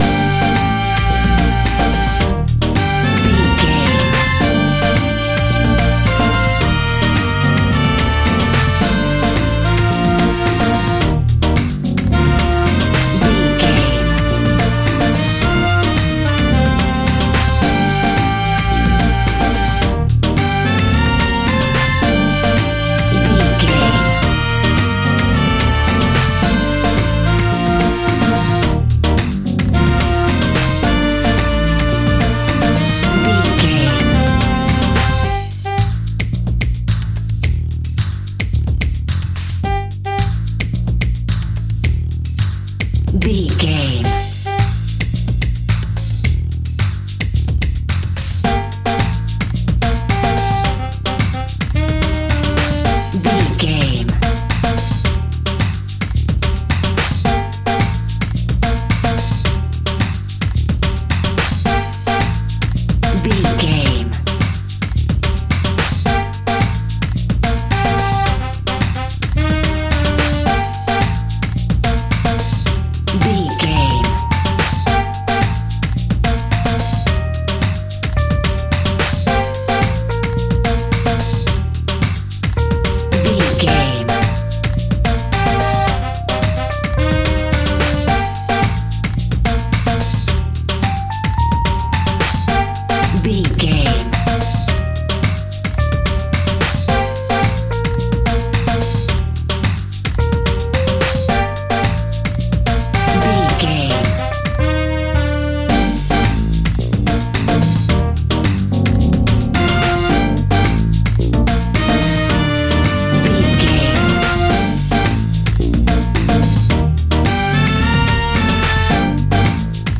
Aeolian/Minor
disco funk
piano
drums
bass